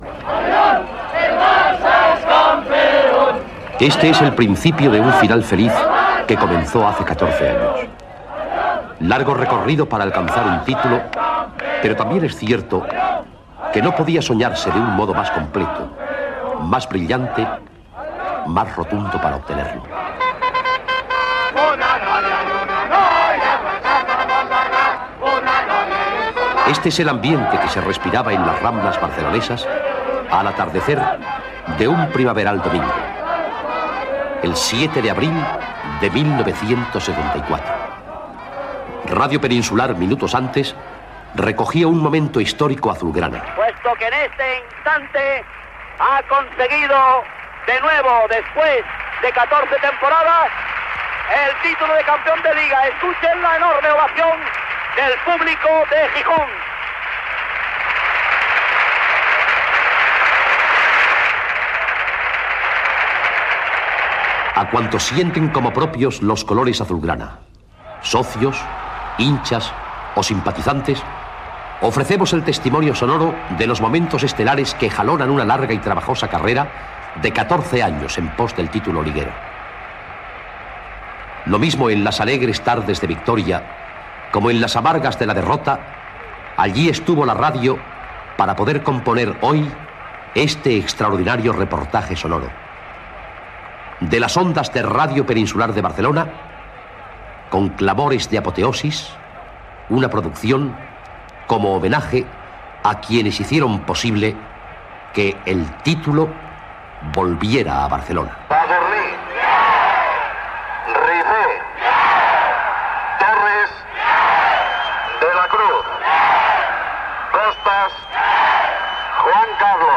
Presentació del programa
Esportiu